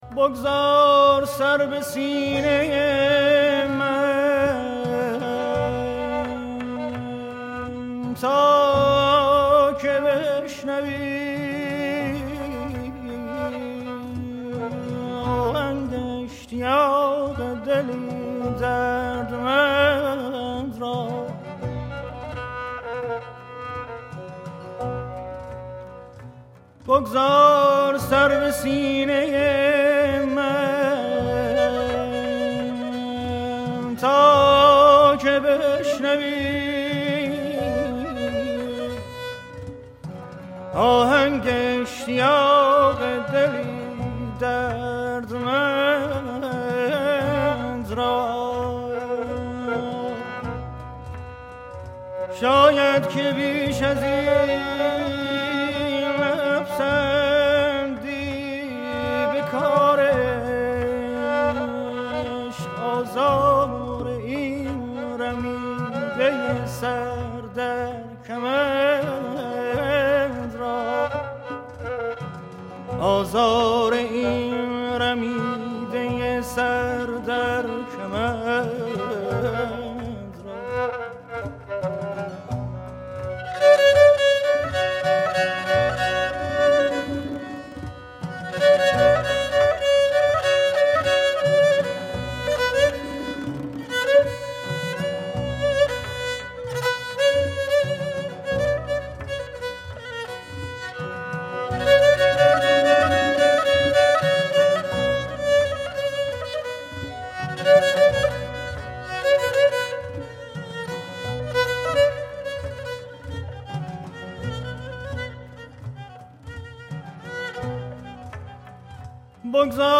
سنتی ایرانی